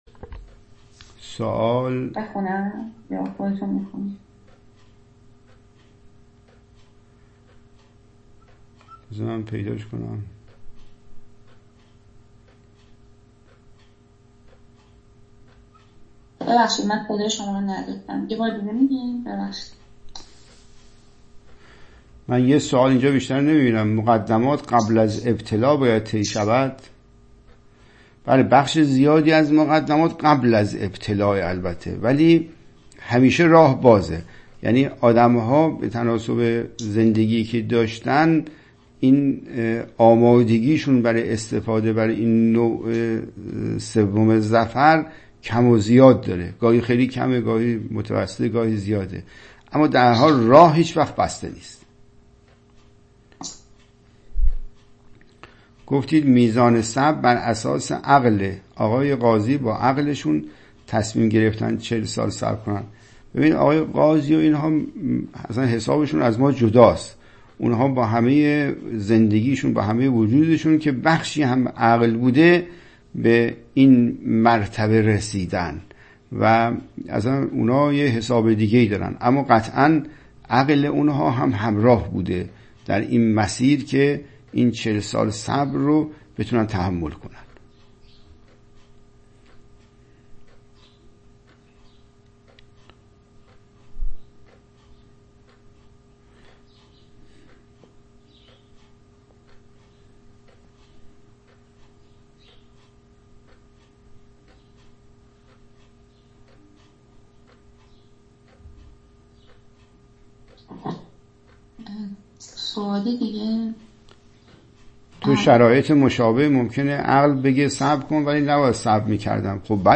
متن : حکمت ۱۵۳ (گفت‌وگو)